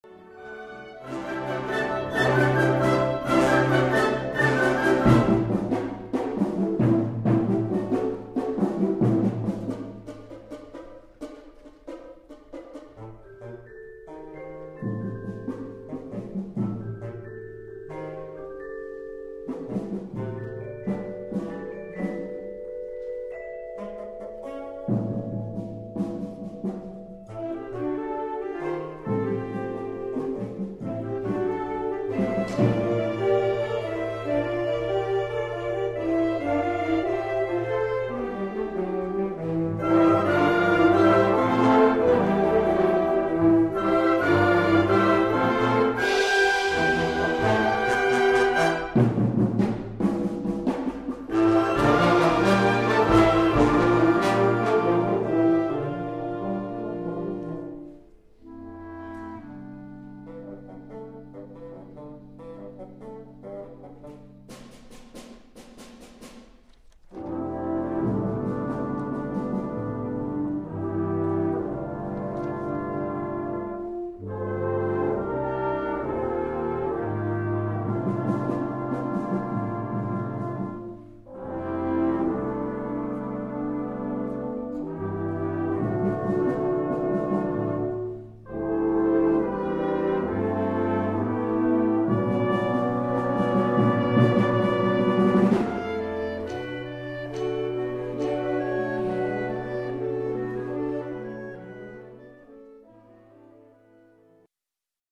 The Swarthmore College Wind Ensemble performs a Suite from Leonard Bernstein's Mass
This concert took place in November 2011.